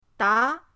da2.mp3